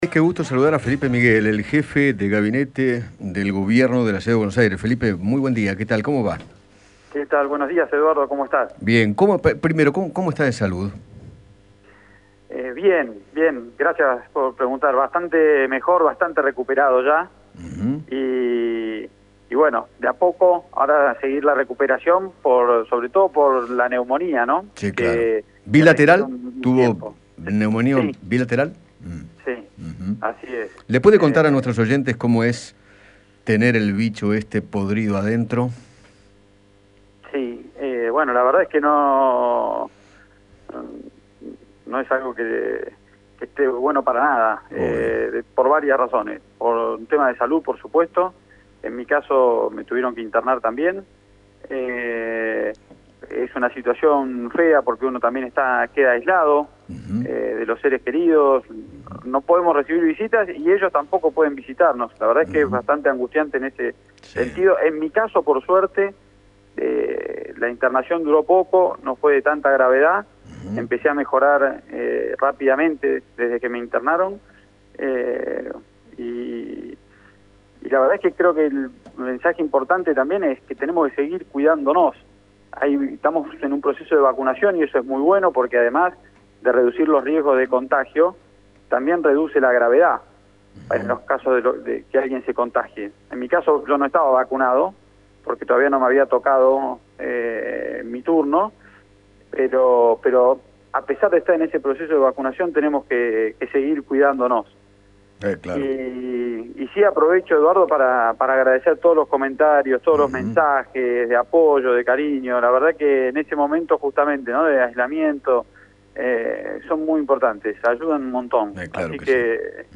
Felipe Miguel, jefe de Gabinete de la Ciudad de Buenos Aires, habló con Eduardo Feinmann sobre las reaperturas en CABA e hizo hincapié en la importancia de respetar los protocolos. Además, se refirió a su recuperación tras contagiarse de coronavirus.